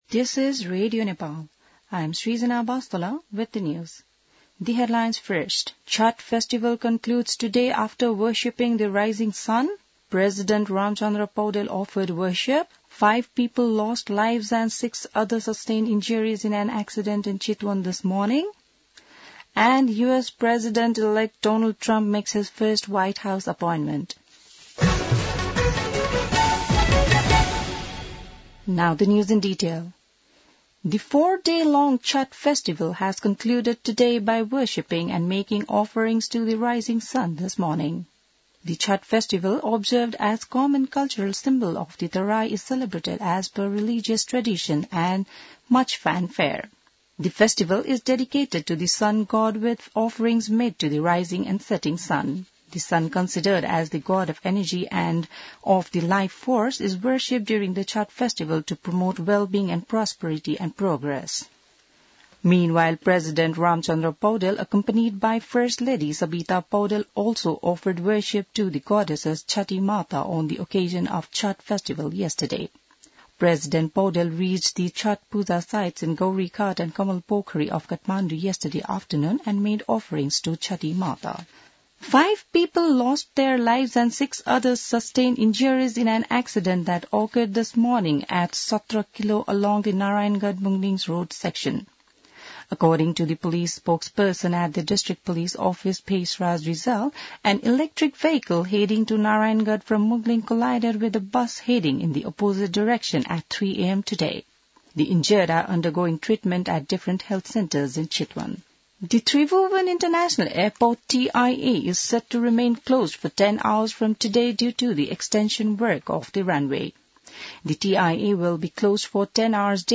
बिहान ८ बजेको अङ्ग्रेजी समाचार : २५ कार्तिक , २०८१